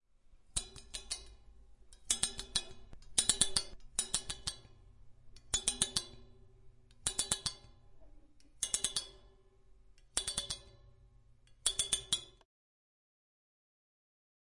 描述：串和干衣机